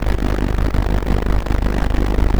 ELECTRICITY_Subtle_loop_mono.wav